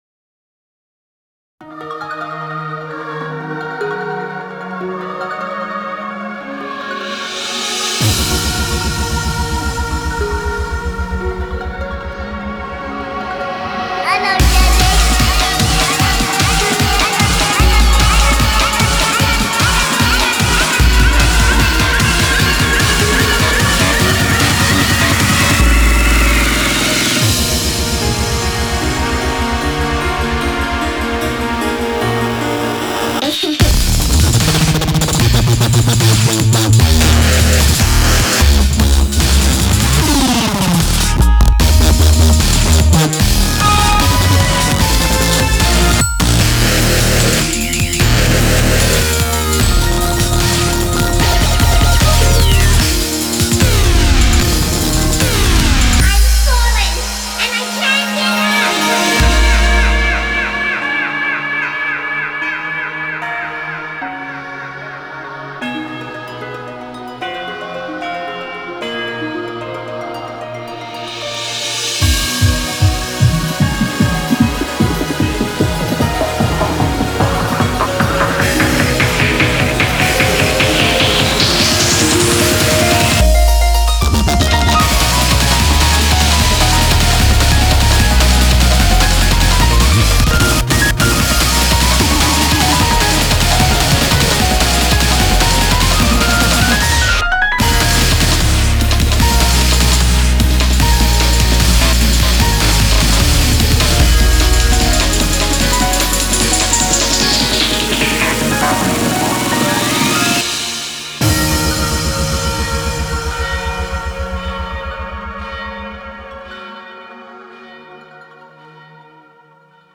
BPM75-300
Audio QualityMusic Cut